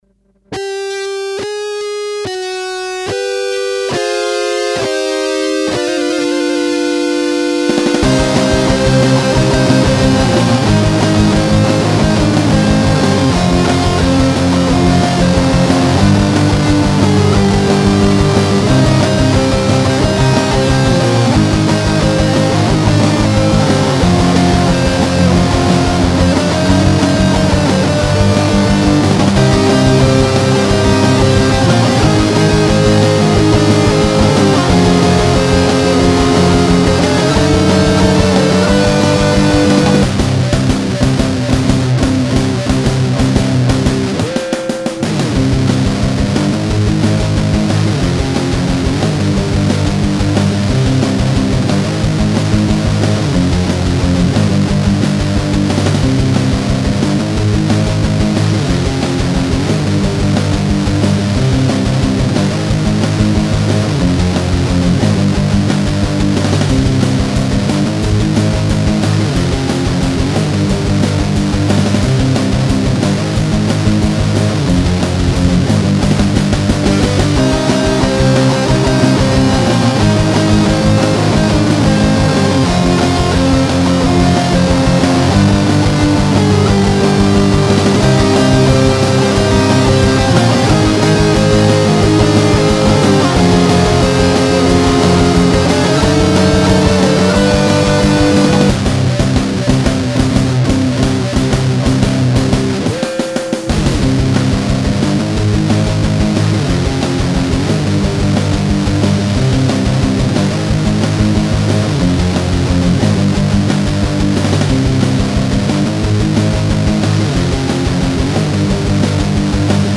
Intro (sans paroles) 0:00 -> 0:45
Solo 2:20 -> 2:52